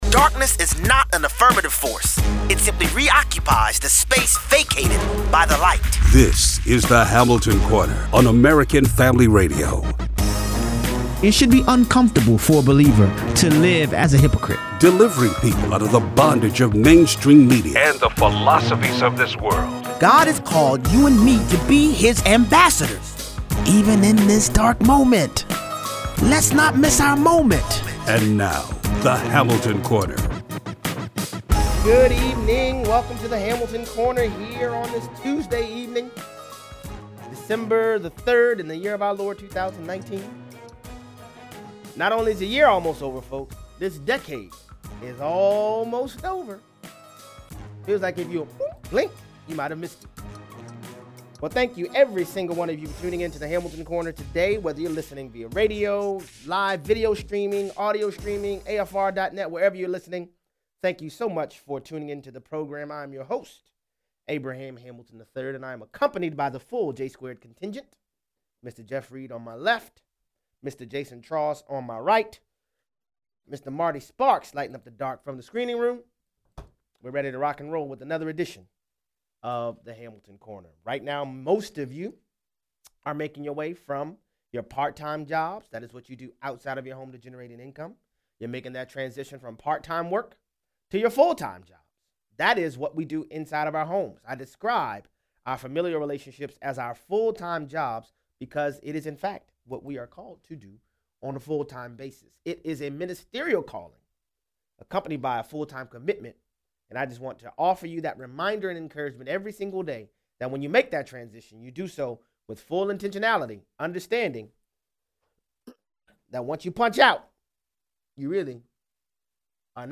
Elizabeth Warren vows to wear pink Planned Parenthood scarf when sworn in as president and she hopes to abolish the Electoral College. Callers weigh in.